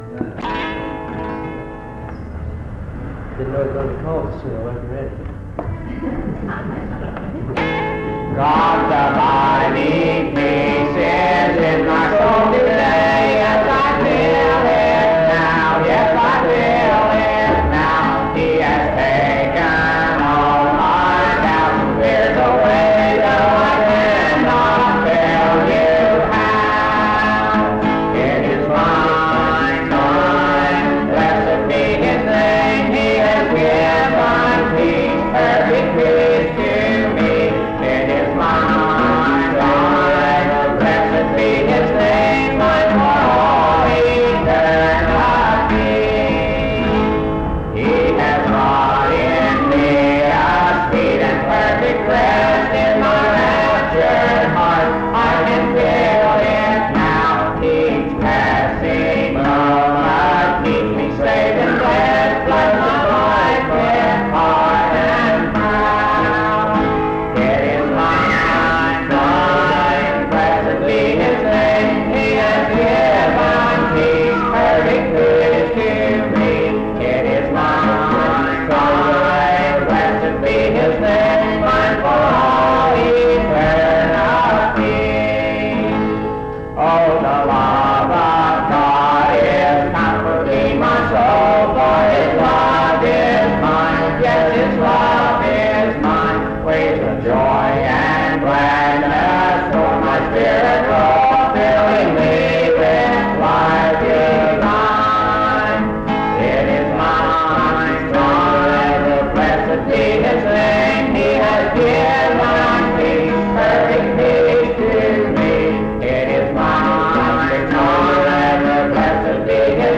Mount Union Methodist Church II, rural, Monongalia County, WV, track 145E.